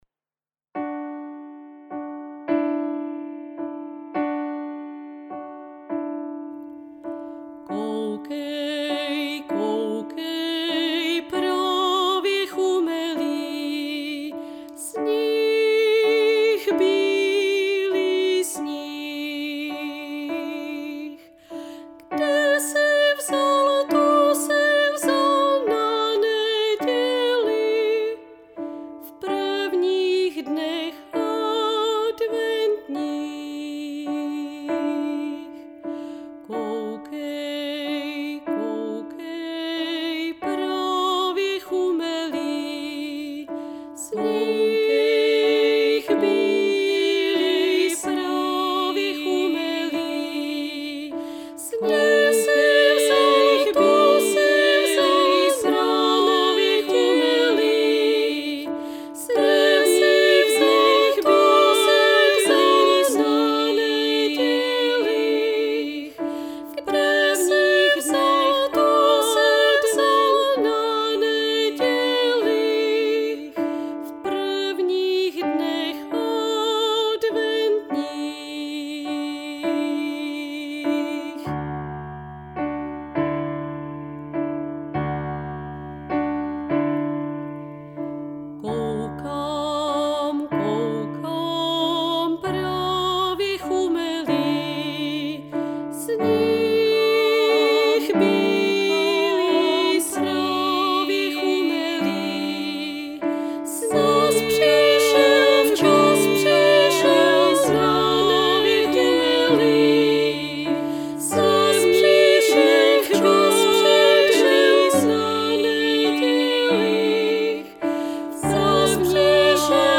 Protože je to kánon, hlasy se postupně přidávají.
4. hlas bude hrát violoncello, ten se učit nemusíte.
Věříme, že se vám to podaří, je to opravdu moc pěkná melodie a i když to zatím tak nevypadá, Martin přijede na bílém koni už brzy…